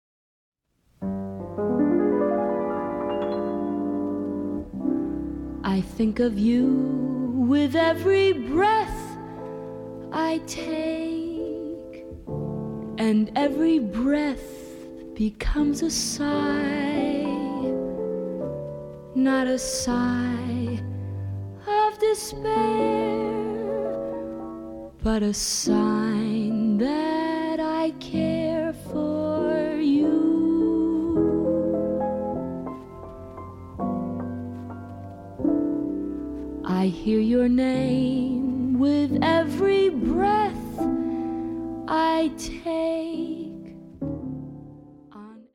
この時代の歌手ならではの明朗快活っぷりに、深く息をするようなデリケートな歌い方もできるアメリカのシンガー
恋をテーマにしたスロー〜ミディアム・テンポの曲でほぼ占められる本作。